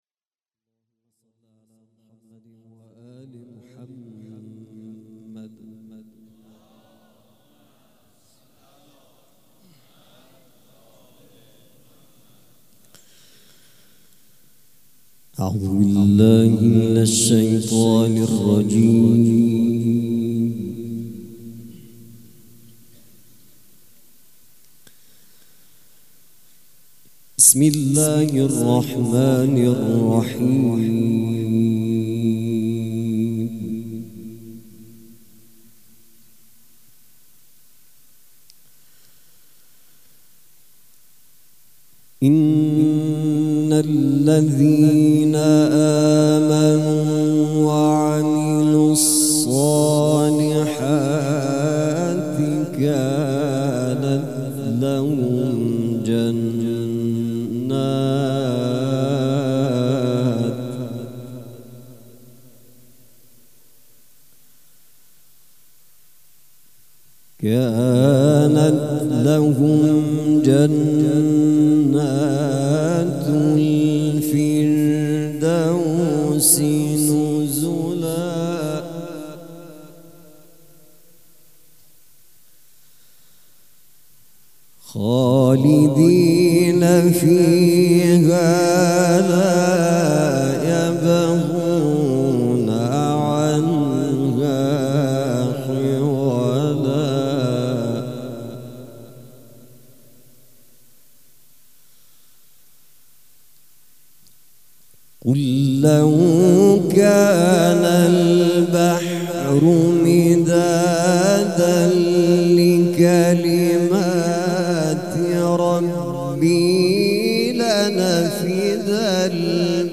قرائت قرآن
مراسم شب اول ماه صفر